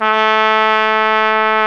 Index of /90_sSampleCDs/Roland L-CDX-03 Disk 2/BRS_Tpt mf menu/BRS_Tp mf menu